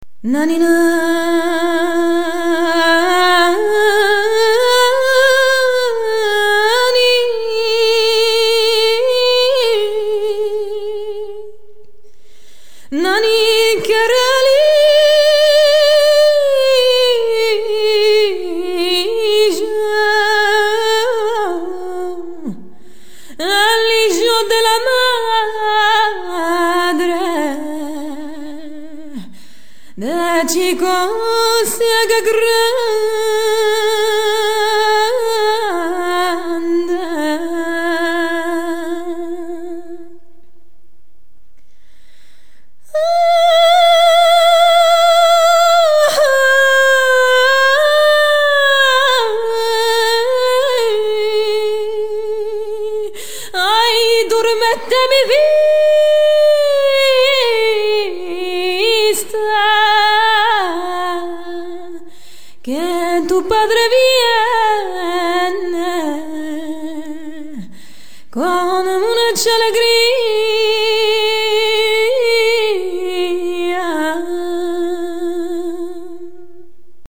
Here’s a recording of part of a song in a mystery language.